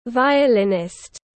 Nghệ sĩ vi-ô-lông tiếng anh gọi là violinist, phiên âm tiếng anh đọc là /ˌvaɪəˈlɪnɪst/.